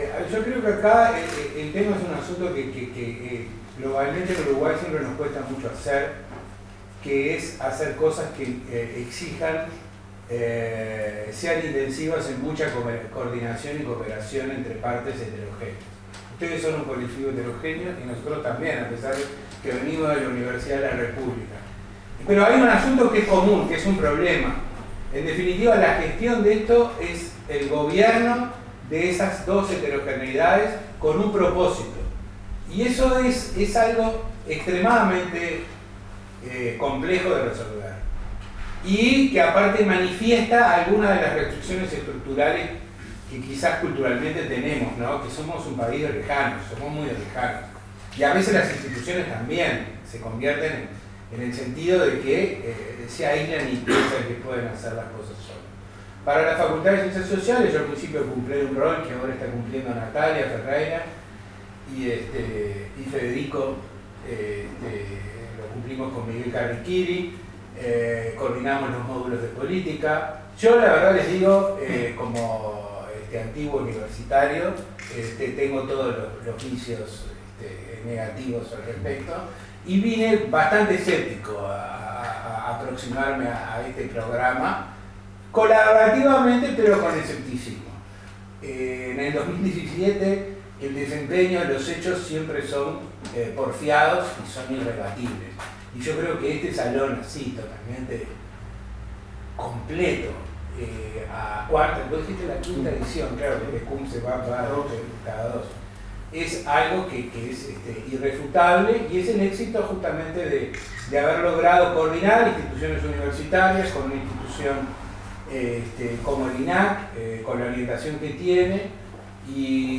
Luego se llevó a cabo la ceremonia de apertura